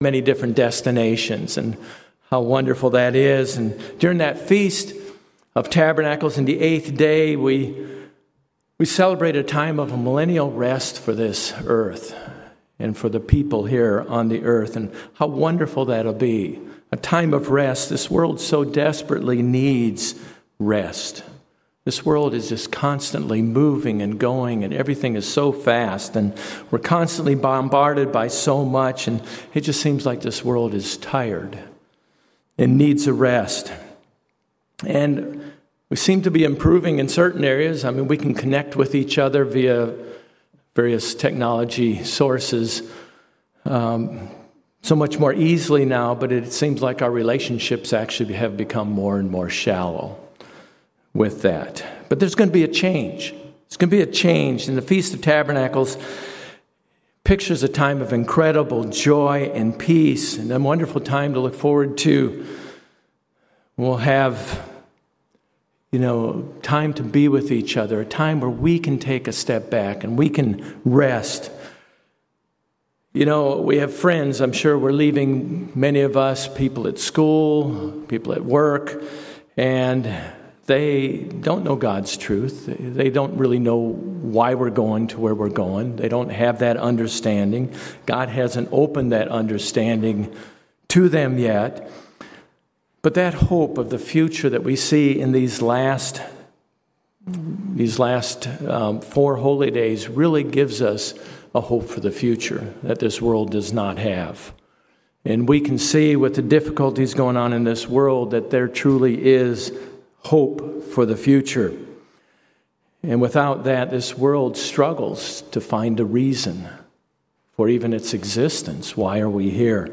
Sermons
Given in Jacksonville, FL